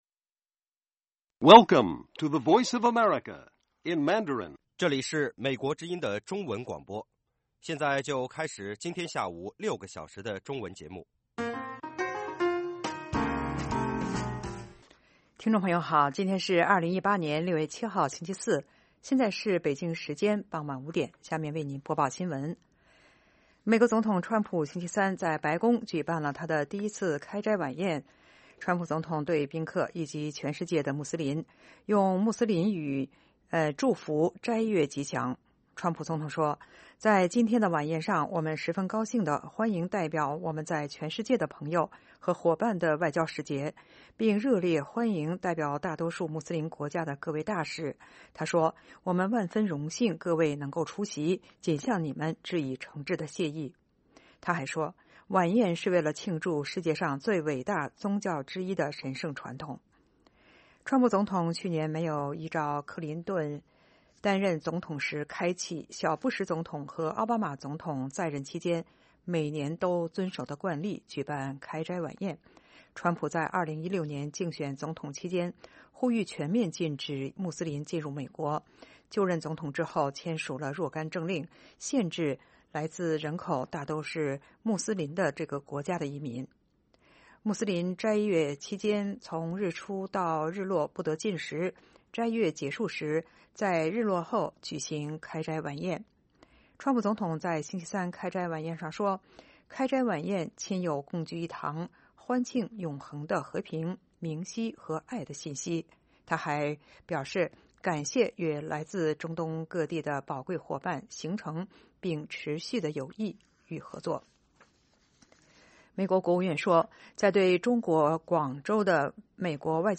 北京时间下午5-6点广播节目。广播内容包括国际新闻，新动态英语，以及《时事大家谈》(重播)